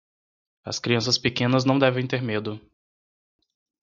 Read more Noun Adj Frequency A1 Pronounced as (IPA) /ˈme.du/ Etymology Inherited from Latin metus Cognate with Spanish miedo Borrowed from Latin Mēdus In summary From Old Galician-Portuguese medo, from Latin metus (“fear”).